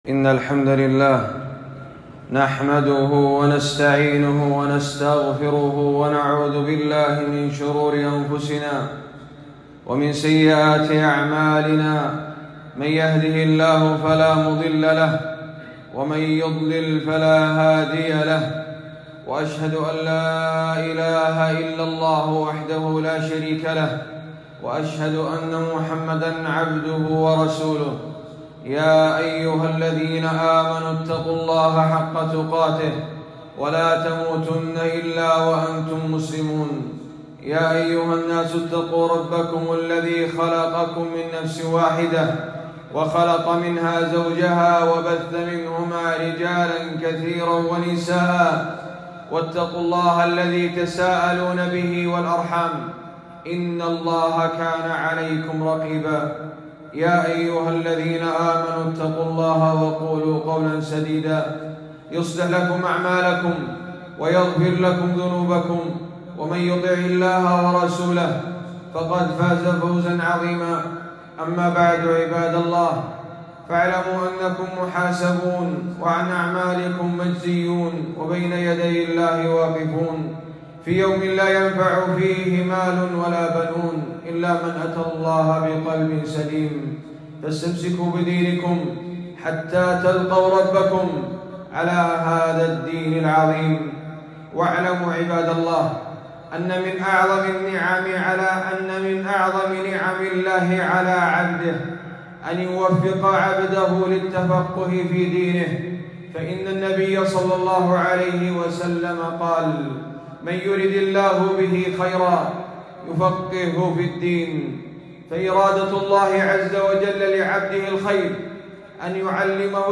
خطبة - فريضة الحج وحكم الحج بلا تصريح